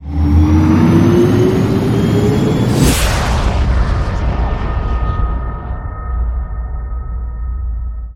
Cosmic Rage / ships / Movement / launch4.wav
launch4.wav